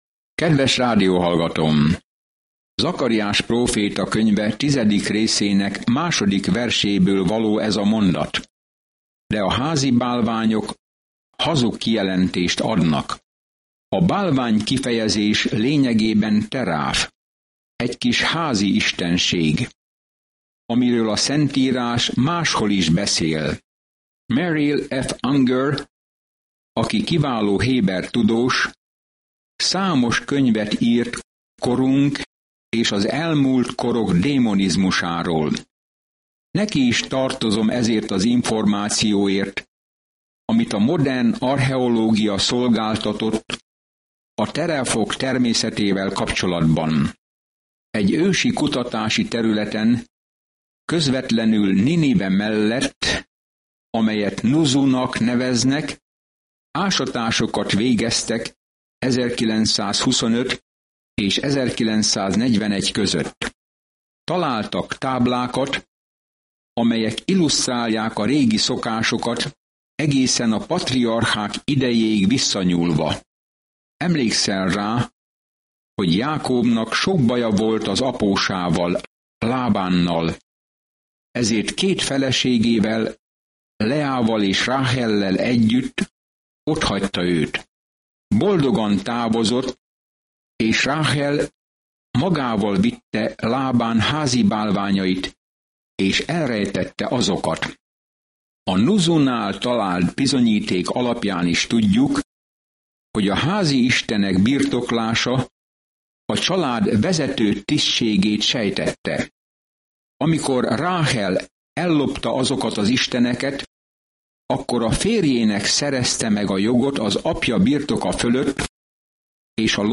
Szentírás Zakariás 10:3-4 Nap 20 Terv elkezdése Nap 22 A tervről Zakariás próféta látomásokat oszt meg Isten ígéreteiről, hogy reményt adjon az embereknek a jövőre nézve, és arra buzdítja őket, hogy térjenek vissza Istenhez. Napi utazás Zakariáson keresztül, miközben hallgatod a hangos tanulmányt, és olvasol válogatott verseket Isten szavából.